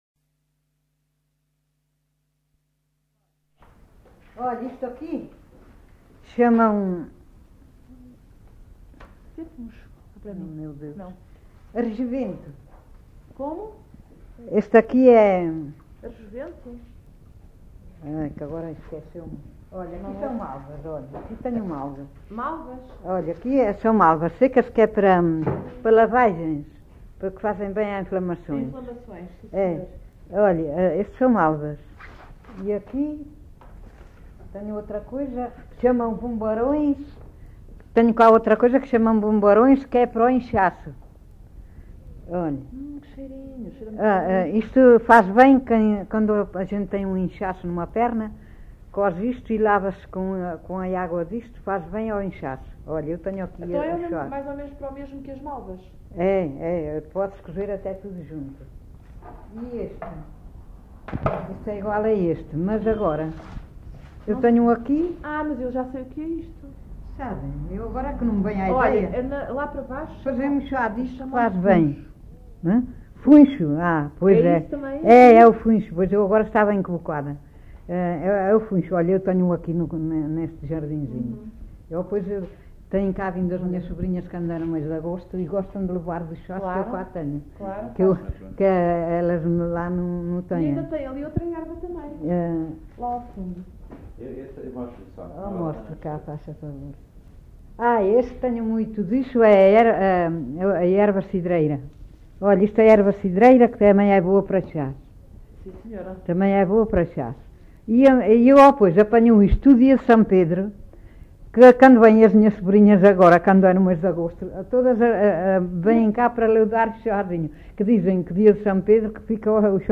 LocalidadeAssanhas (Celorico da Beira, Guarda)